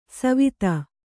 ♪ savita